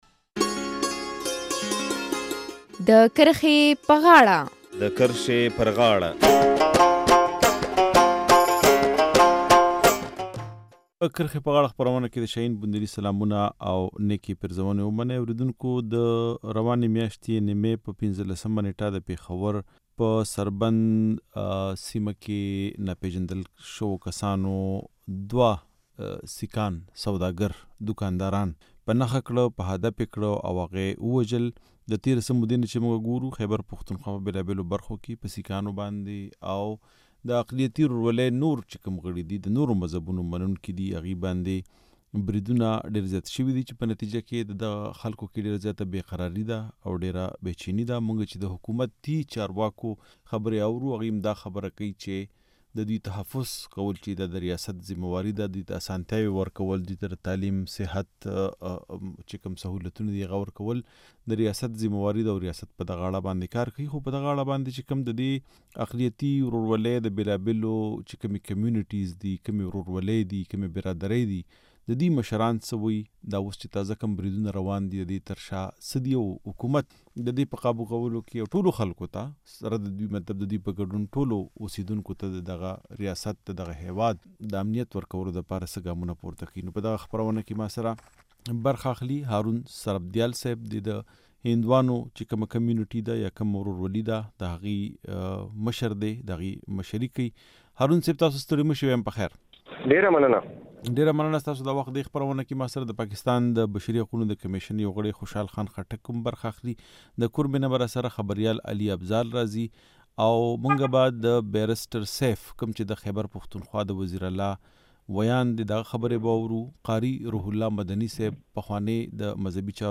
په پېښور کې د دوو سيکانو تر وژل کېدو وروسته دا بحثونه راپورته شوي چې د دې مذهبي اقليت ژوند څنګه خوندي کېدلی شي. په همدې اړه د کرښې په غاړه خپرونه کې د سیکانو استازو، ديني عالمانو او چارواکو بحث لرو.